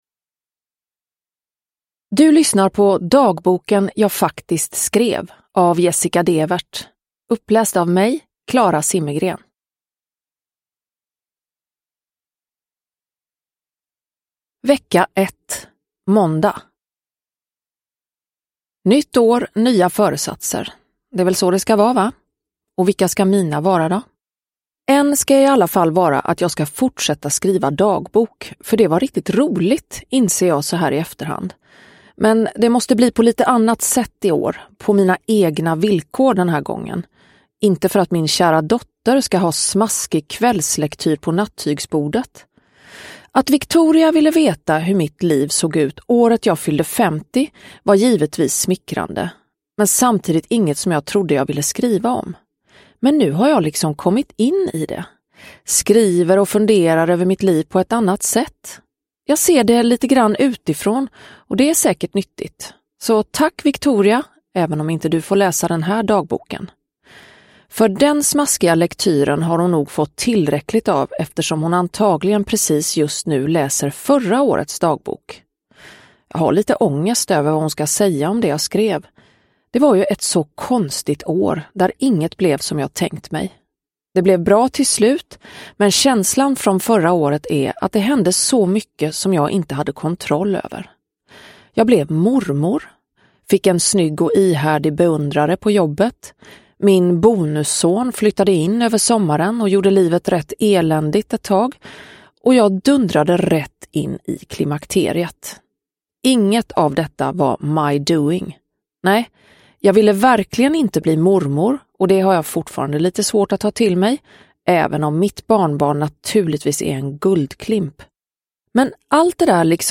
Dagboken jag faktiskt skrev – Ljudbok – Laddas ner
Uppläsare: Klara Zimmergren